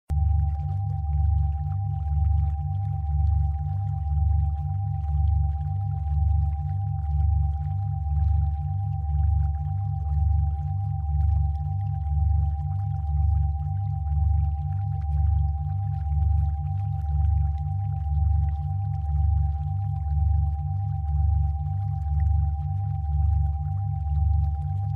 ALIVIO INMEDIATO DE LA MIGRAÑA - RITMOS BINAURALES PUROS Y FRECUENCIAS RIFE.